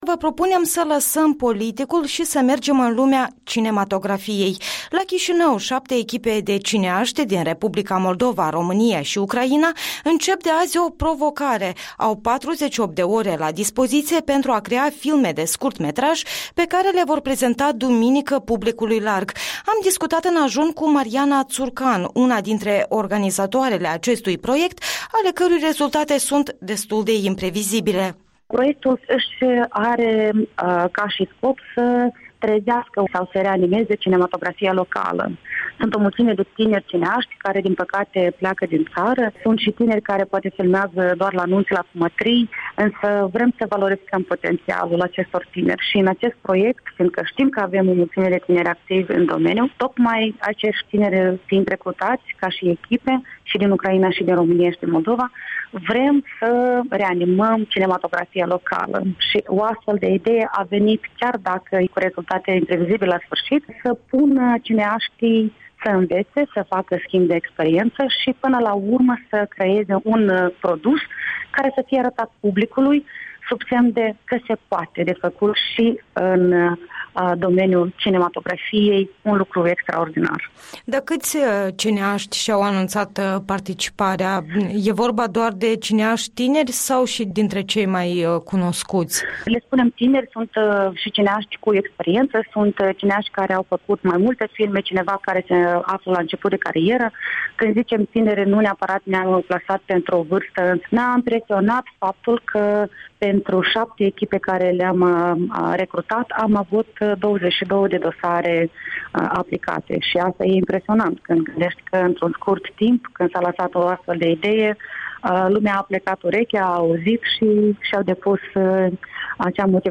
Interviul dimineții cu o consultată a unui original proiect de film în curs la Chișinău.